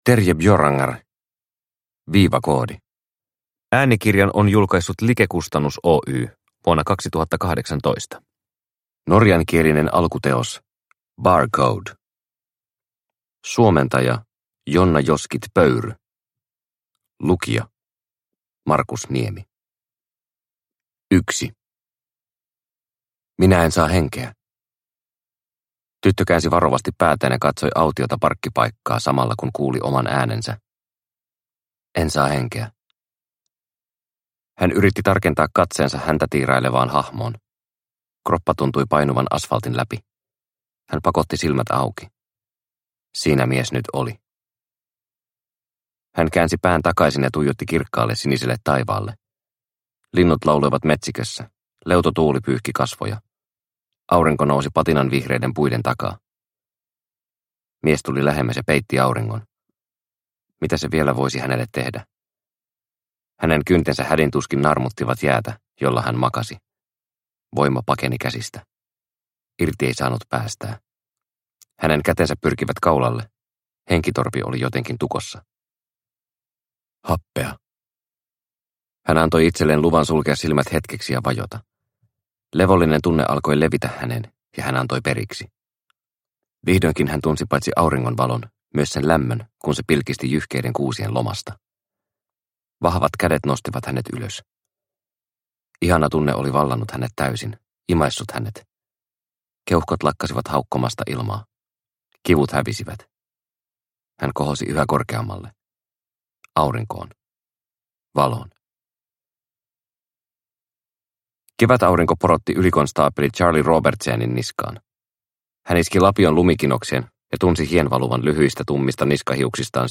Viivakoodi – Ljudbok – Laddas ner